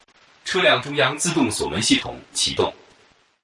Tesla Lock Sound Chinese Mandarin Woman (Copie)
Chinese Mandarin male voice saying
(This is a lofi preview version. The downloadable version will be in full quality)
JM_Tesla-Lock_Chinese-Mandarin_Man_Watermark.mp3